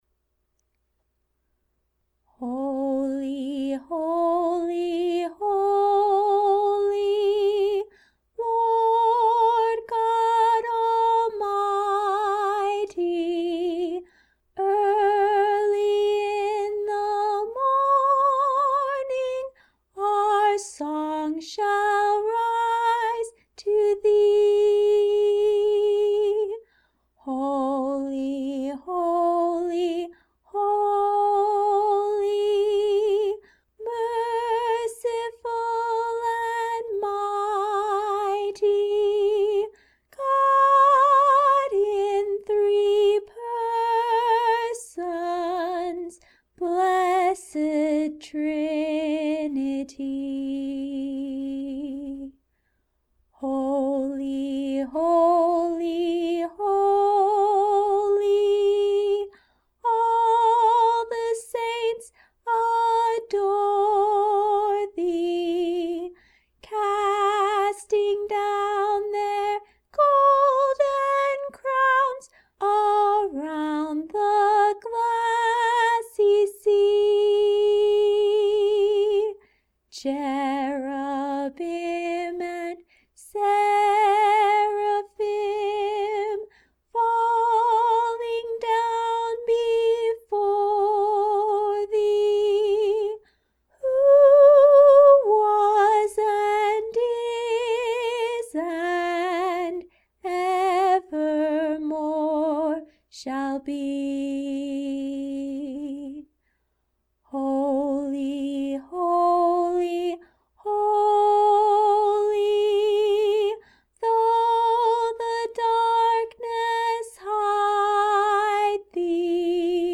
Hymn lyrics and .mp3 Download
Vocal